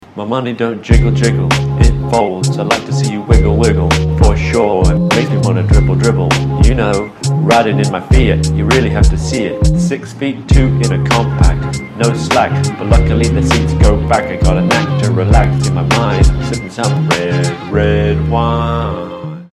• Качество: 320, Stereo
мужской голос
спокойные
смешные
тихие